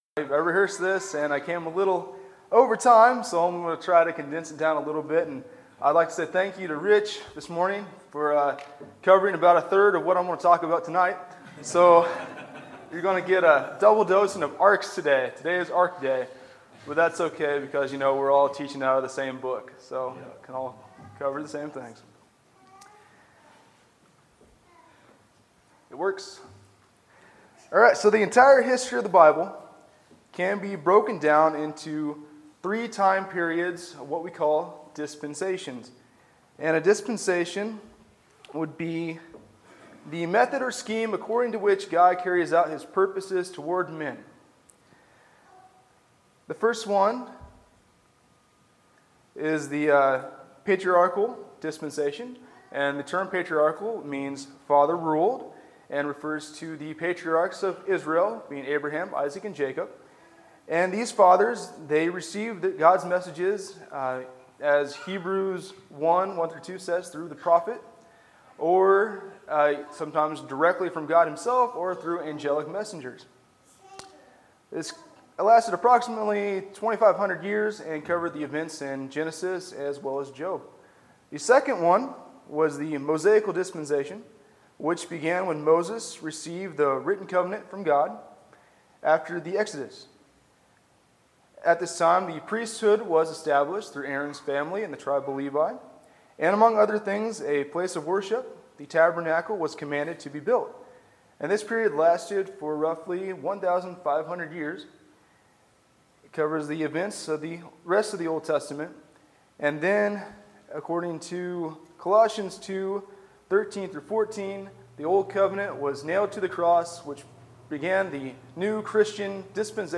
Sermons, August 27, 2017